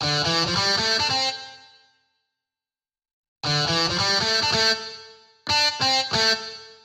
标签： 140 bpm Electronic Loops Guitar Electric Loops 1.15 MB wav Key : Unknown
声道立体声